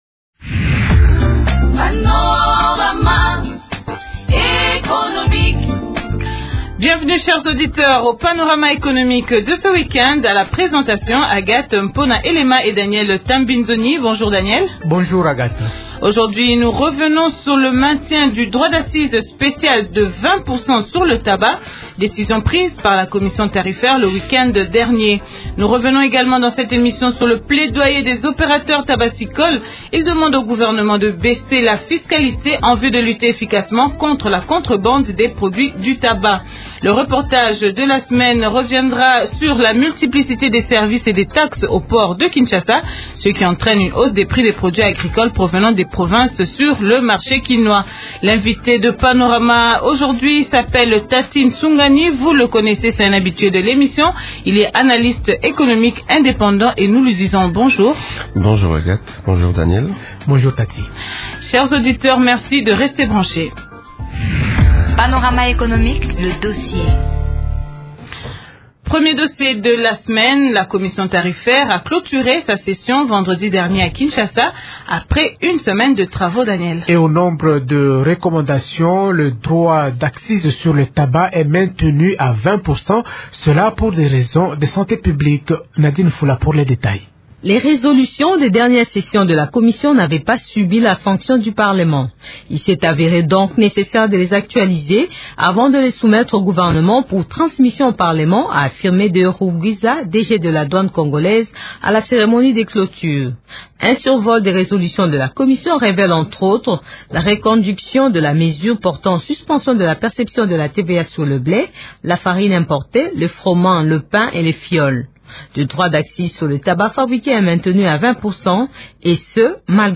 analyste économique indépendant.